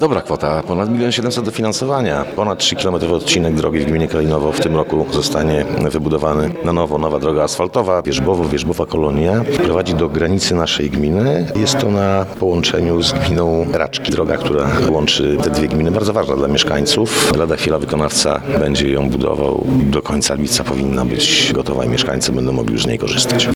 – Pieniądze przeznaczymy na modernizację drogi do gminy Raczki. Remont powinien się skończyć w lipcu – mówi wójt gminy Kalinowo Andrzej Bezdziecki.